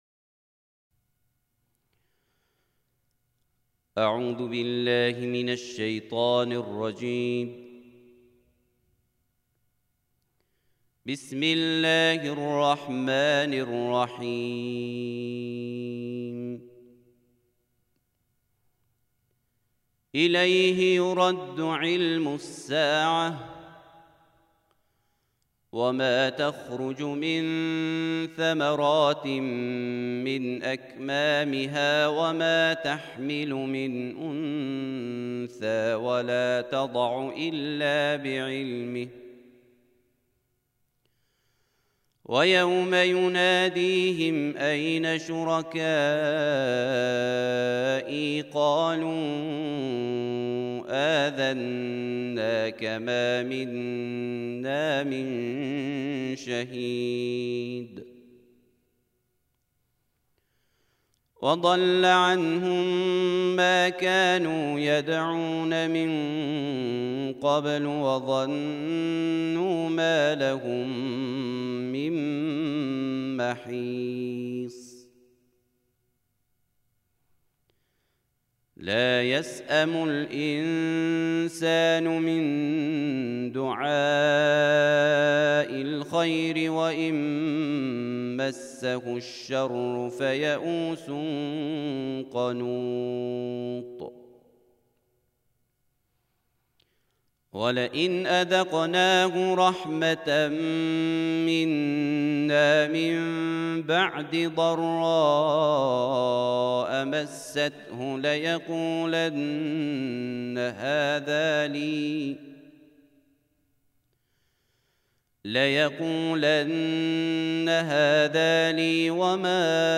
فیلم ‌| تلاوت ترتیل جزء بیست‌وپنجم قرآن کریم